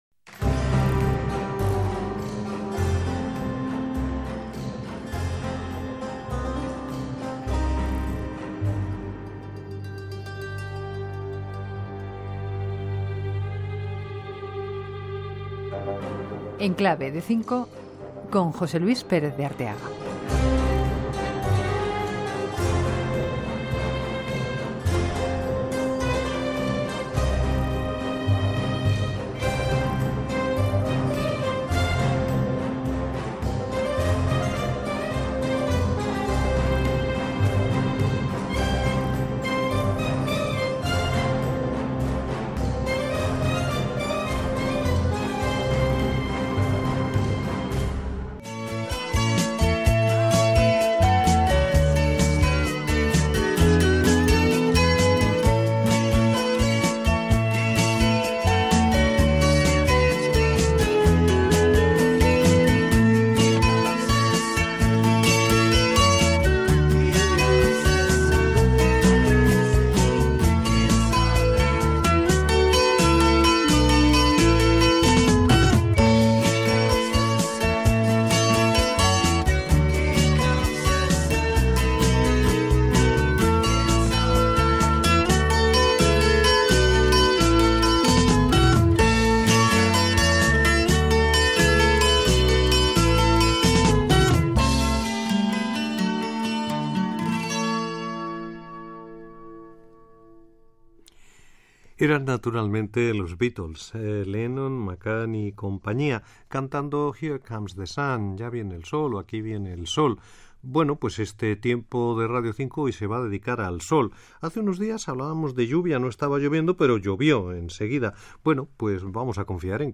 Careta del programa i presentació de diversos temes musicals relacionats amb el sol
Musical